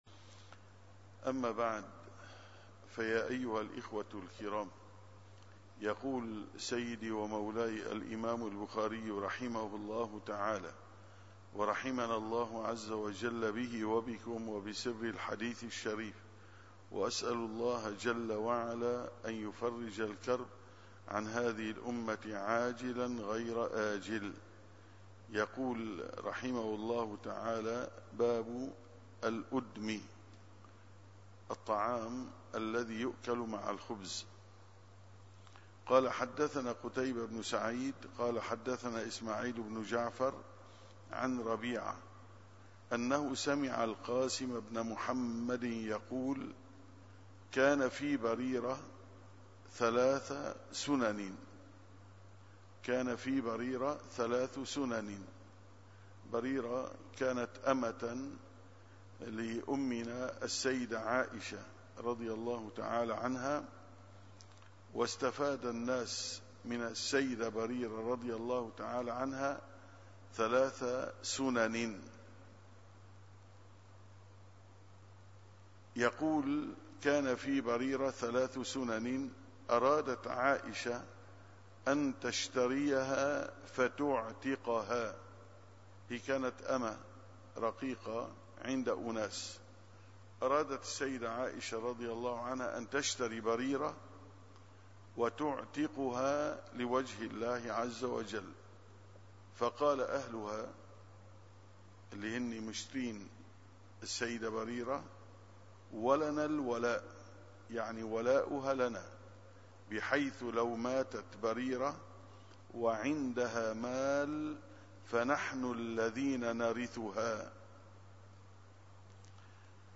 دروس في الحديث الشريف